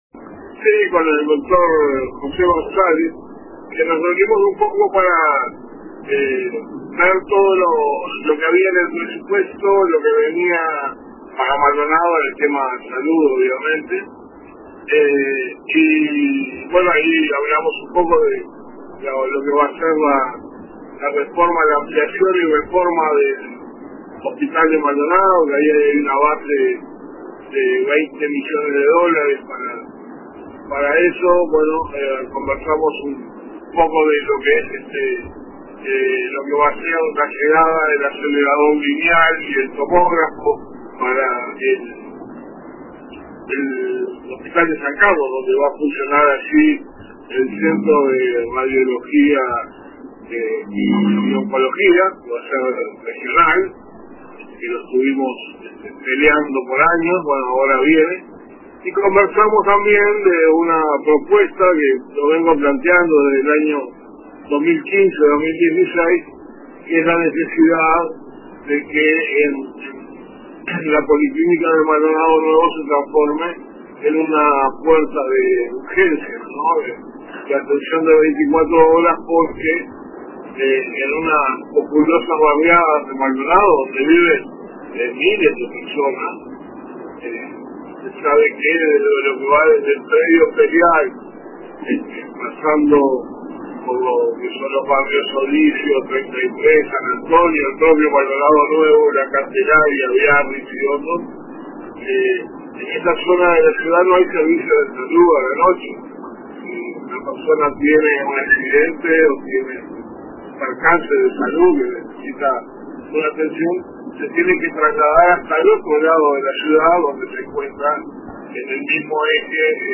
El senador Eduardo Antonini participó del programa Radio con Todos de RBC, donde abordó diversos temas vinculados a la salud en el departamento de Maldonado.